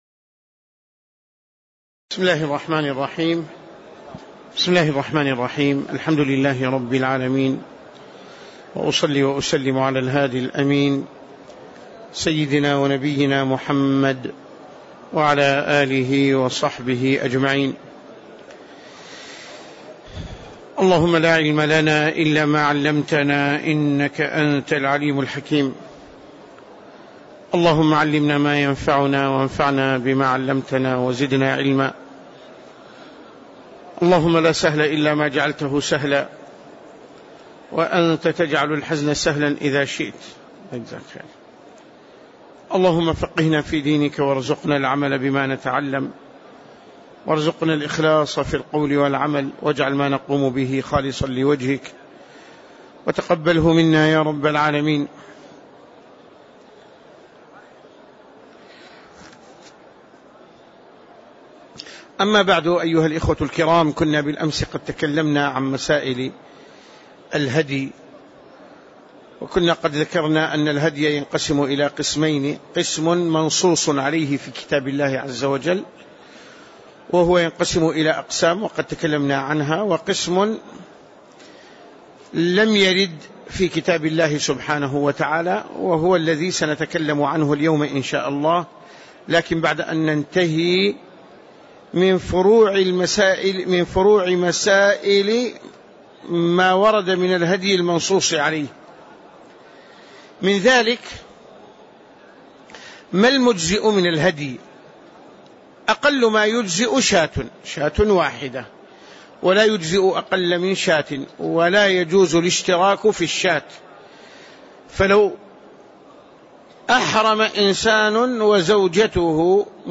تاريخ النشر ٢٥ ذو القعدة ١٤٣٧ هـ المكان: المسجد النبوي الشيخ